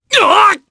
Gladi-Vox_Damage_jp_03_b.wav